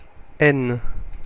Ääntäminen
Ääntäminen France (Paris): IPA: [ɛn] Tuntematon aksentti: IPA: /n/ Haettu sana löytyi näillä lähdekielillä: ranska Käännöksiä ei löytynyt valitulle kohdekielelle.